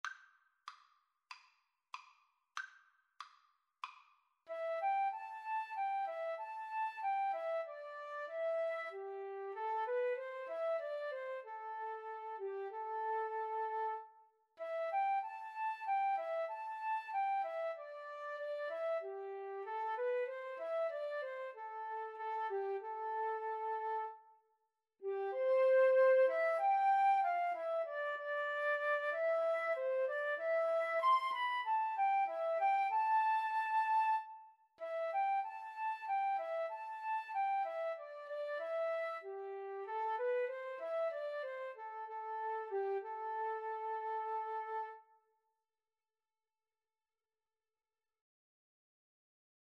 C major (Sounding Pitch) (View more C major Music for Violin-Flute Duet )
= 95 Moderato
4/4 (View more 4/4 Music)
Violin-Flute Duet  (View more Easy Violin-Flute Duet Music)